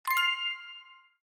jackpot_missed_sound.dabecff9.mp3